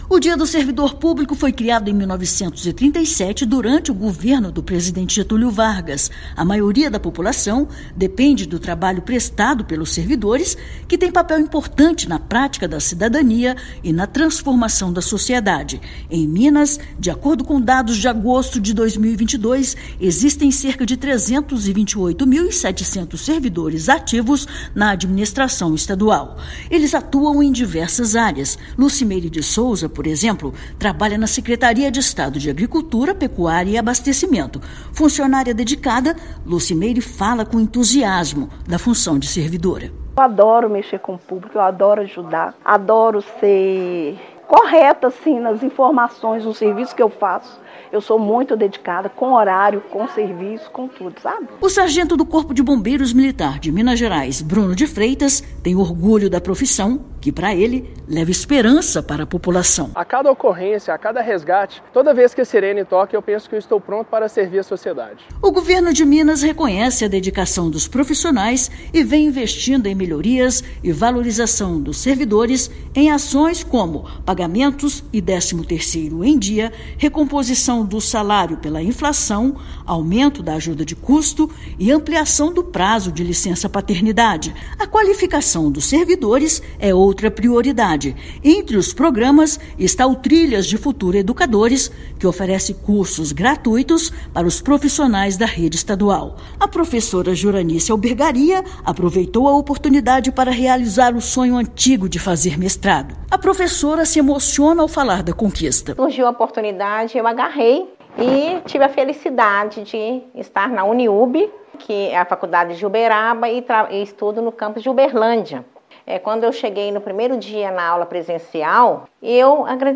Ações do Governo de Minas fortalecem a categoria e aprimoram o serviço prestado à população. Ouça matéria de rádio.